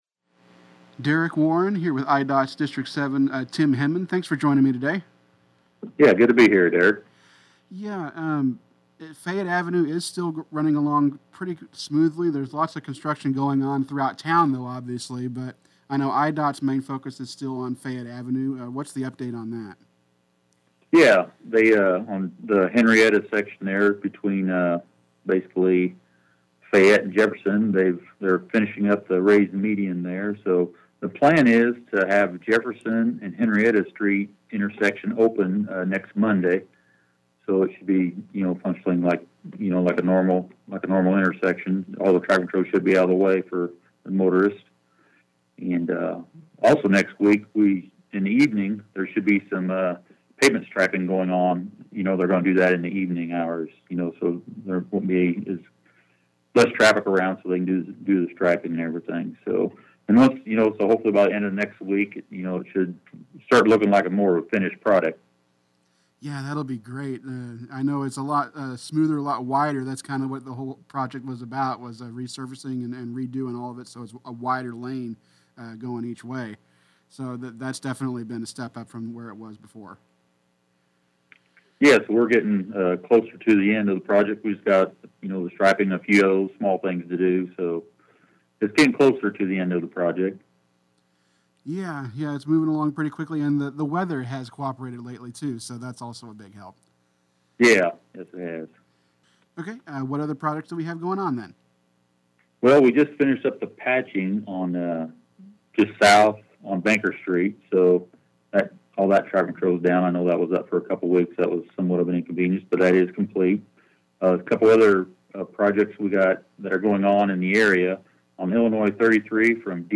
Monthly Update On Fayette Avenue, Other Area Construction Projects